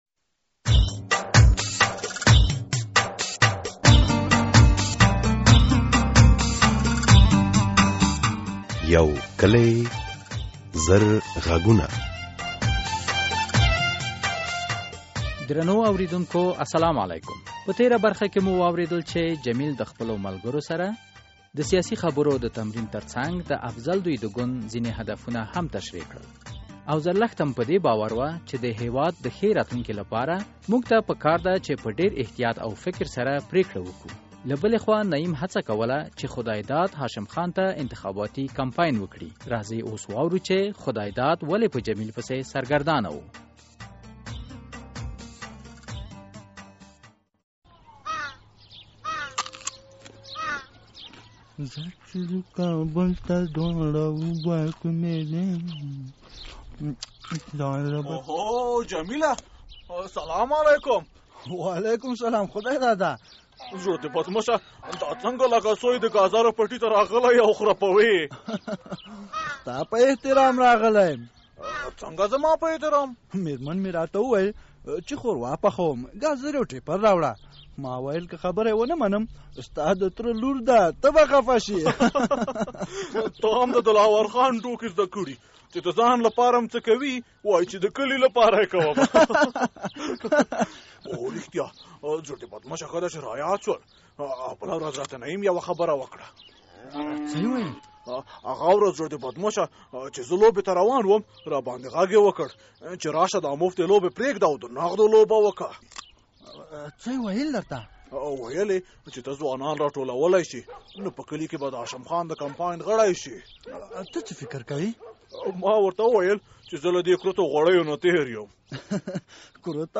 دا ډرامه چې ترډیره د ښځو او ځوانانو حقوقي مسایل پکې نغښتي له دې وروسته هره اونۍ په منظمه توګه خپریږي او تاسو کولای شي په اړه یې خپل نظرونه را ولیږئ.